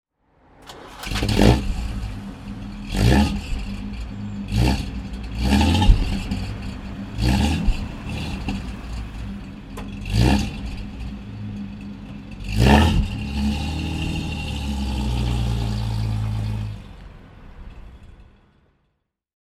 Bentley Continental (1953) - the six-cylinder engineBentley Continental (1953) - the six-cylinder engine
Bentley Continental R Graber DHC (1954) - Starten und losfahren